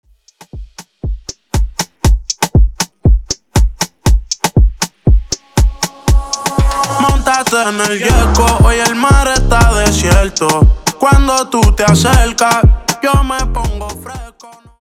Coro Dirty